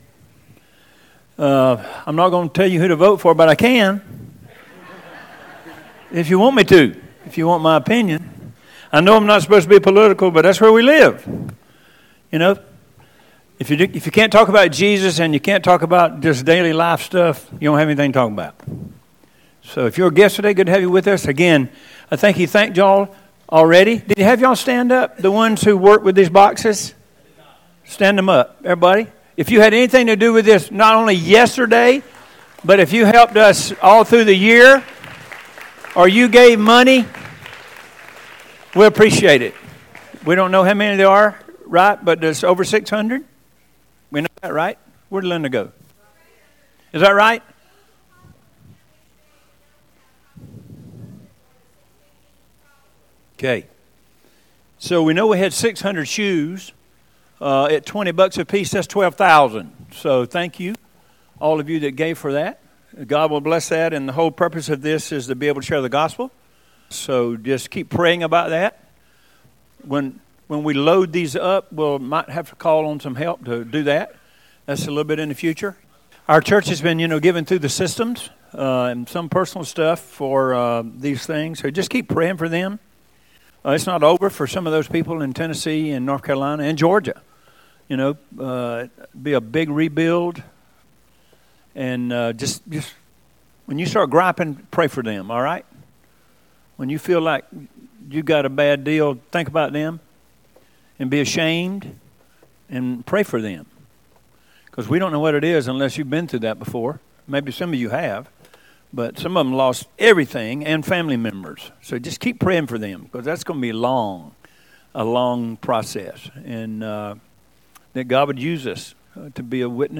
1103Sermon.mp3